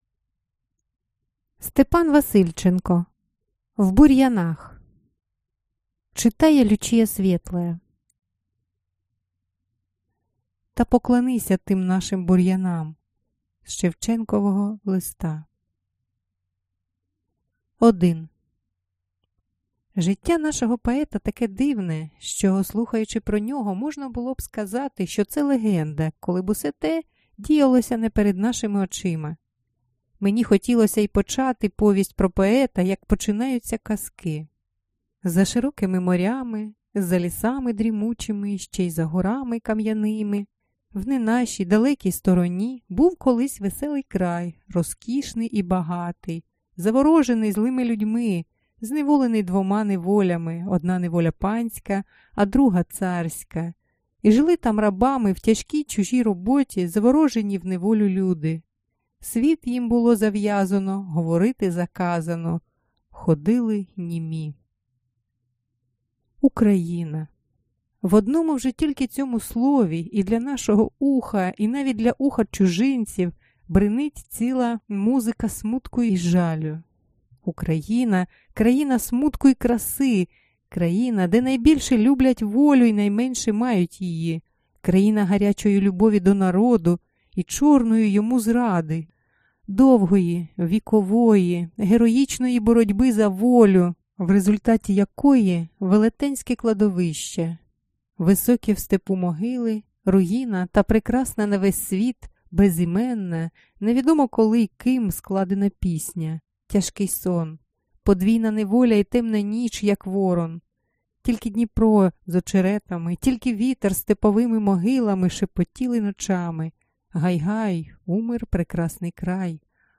Аудиокнига В бур'янах | Библиотека аудиокниг